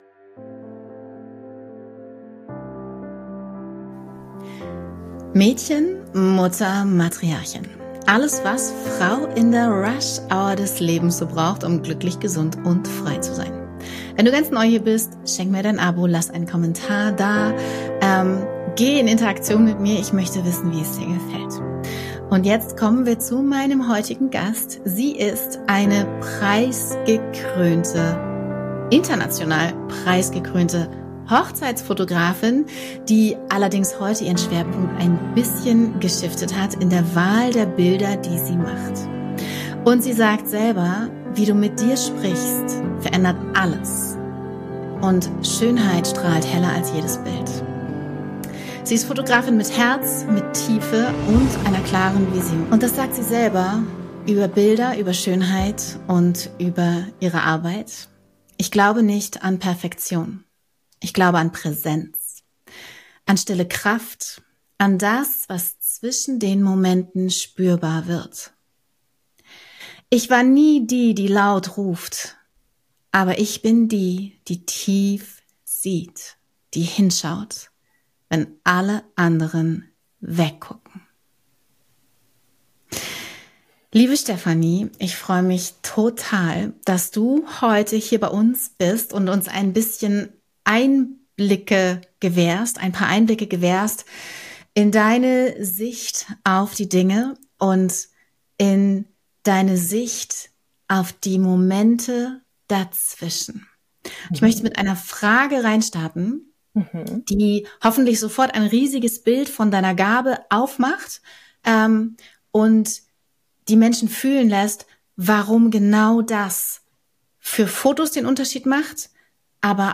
In diesem Gespräch geht es um die tiefgreifende Verbindung zwischen Fotografie, Selbstwahrnehmung und innerer Akzeptanz.